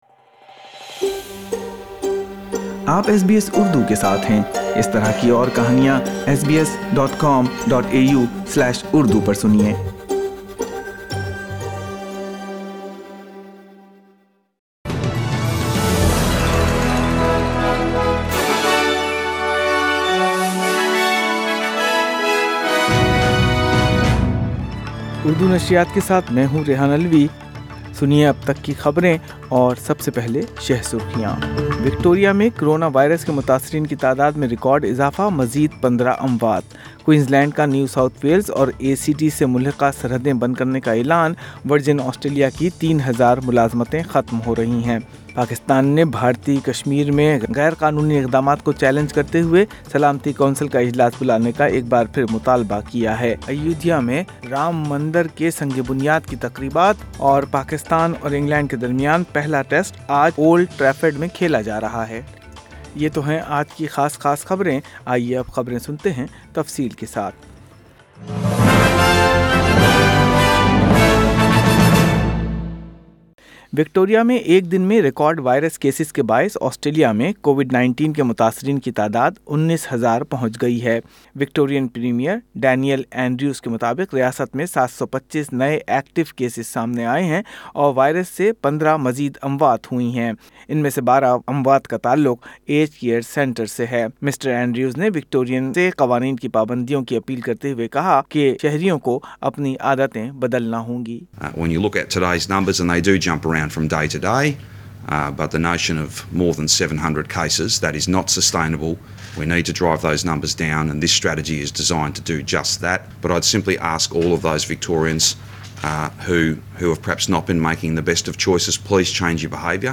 اردو خبریں 5 جولائی 2020